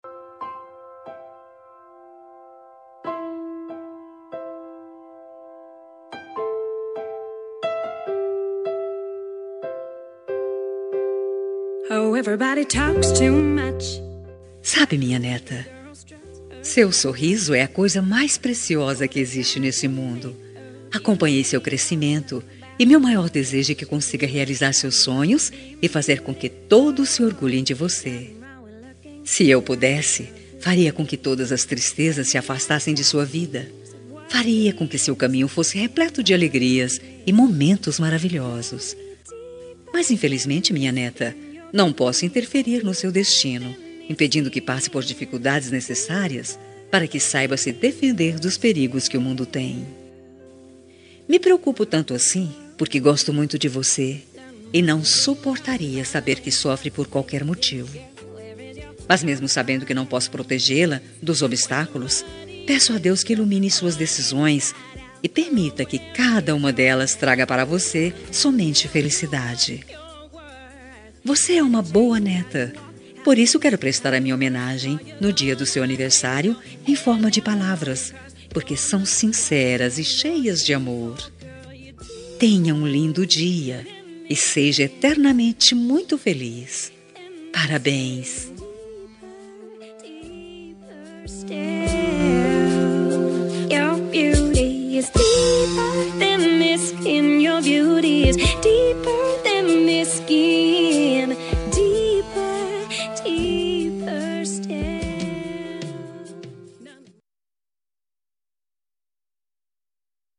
Aniversário de Neta – Voz Feminina – Cód: 131028